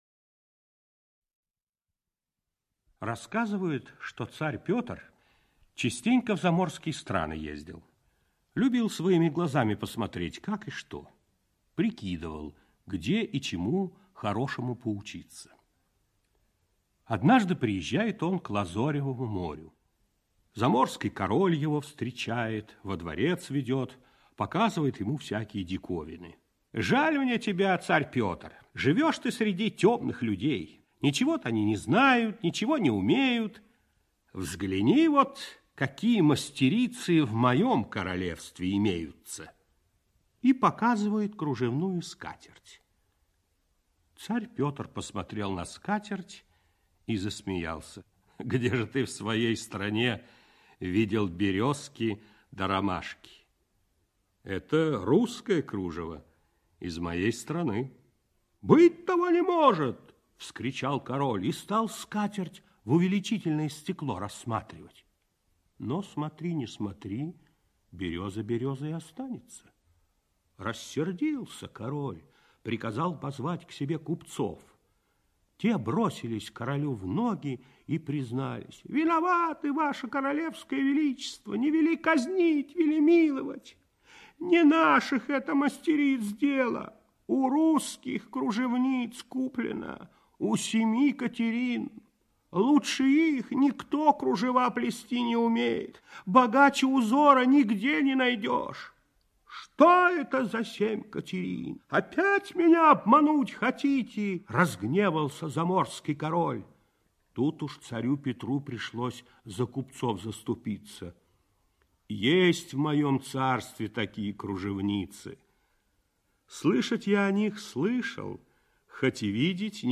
Отчего снежинки разные - аудиосказка Триновой - слушать онлайн